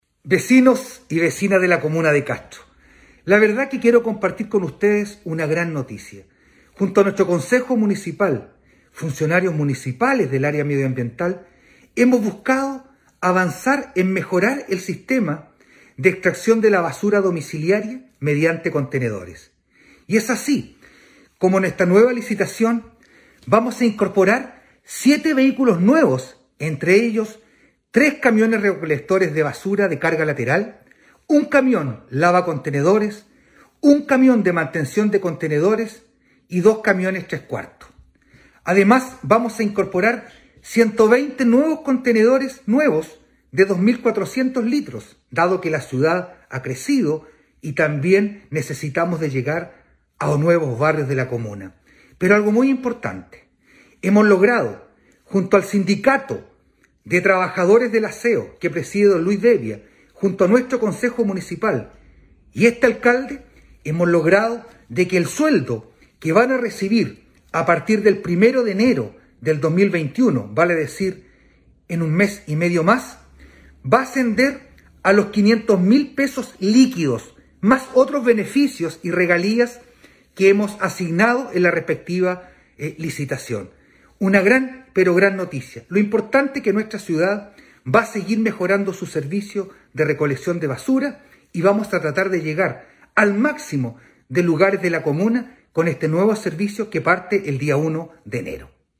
ALCALDE-VERA-NUEVO-TRATO-TRABAJADORES-ASEO.mp3